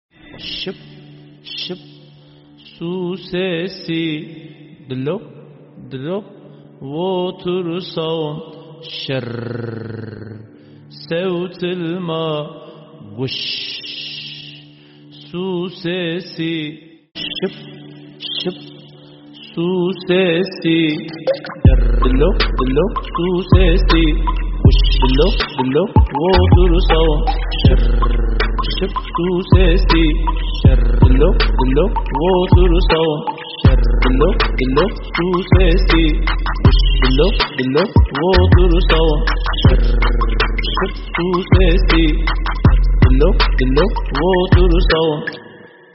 Kategori Müzik